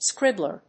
音節scríb・bler 発音記号・読み方
/ˈskrɪblɝ(米国英語), ˈskrɪblɜ:(英国英語)/